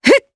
Ripine-Vox_Attack2_jp.wav